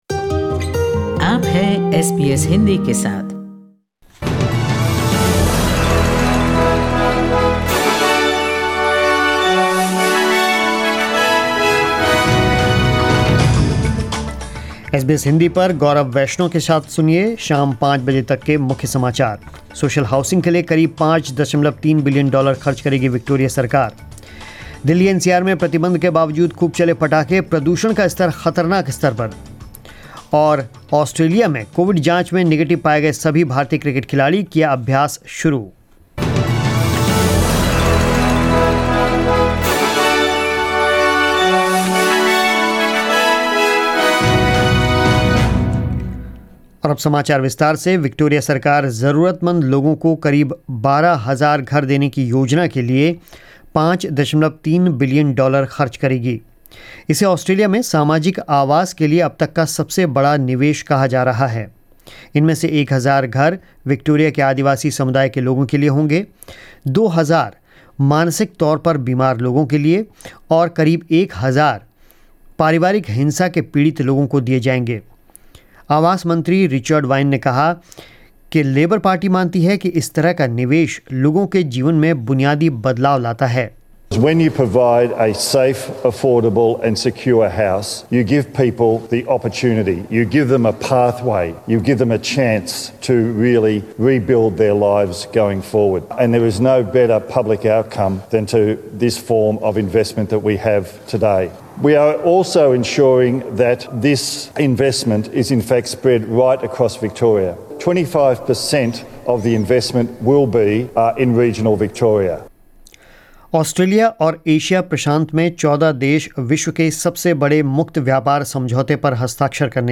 News in Hindi 15 November 2020